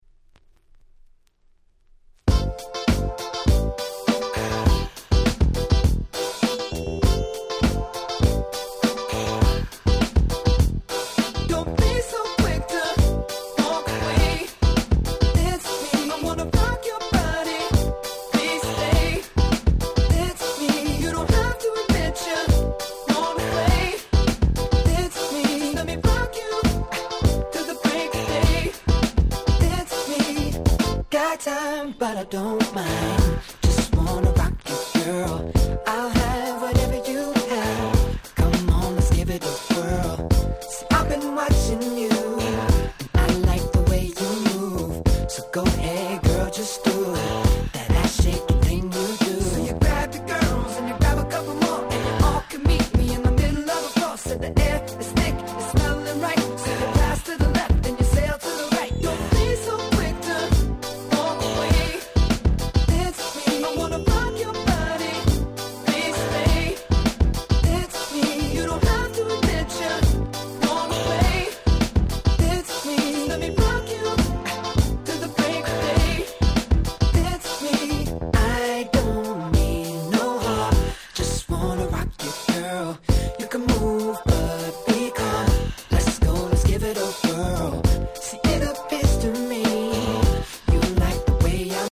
※試聴ファイルは別の盤から録音してございます。